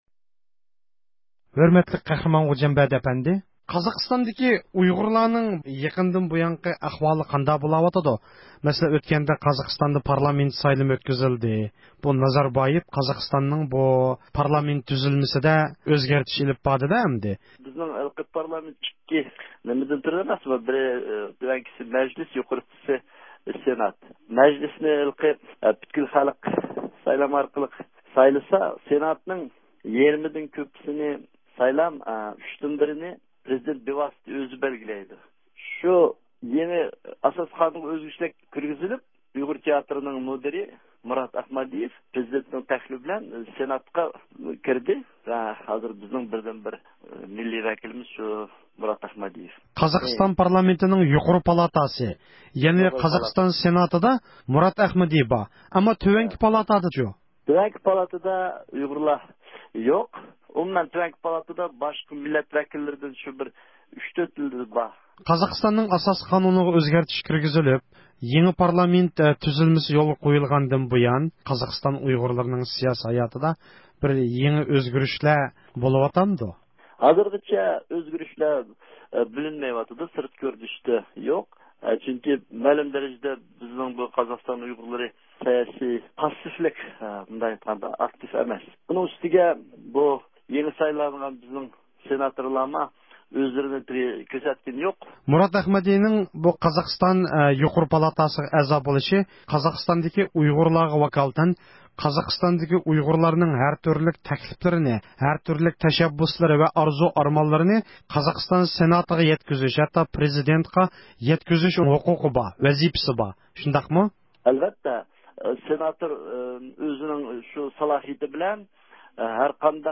تېلېفون سۆھبىتىنى ئاڭلايسىلەر.